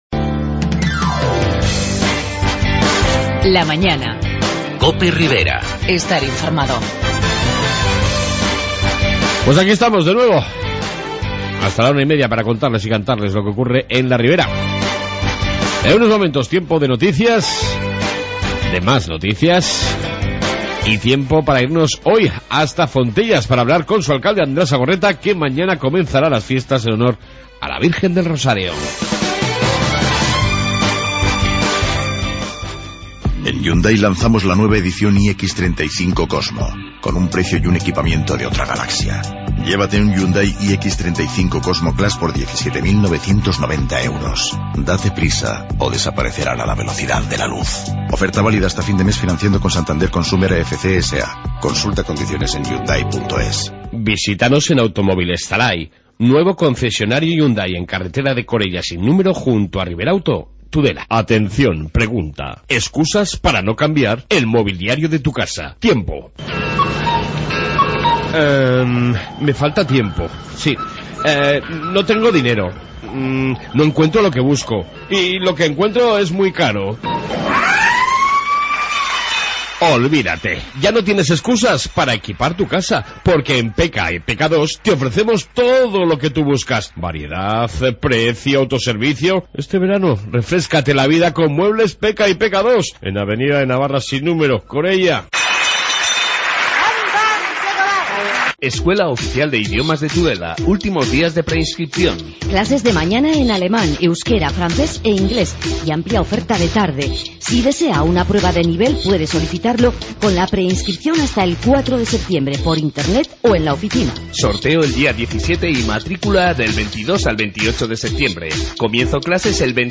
AUDIO: Noticias Riberas y entrevista con el Alcalde de Fontellas, Andrés Agorreta, ante el inicio de las Fiestas Patronales mañana miercoles...